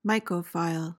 PRONUNCIATION: (MY-ko-fyl) MEANING: noun: A mushroom enthusiast.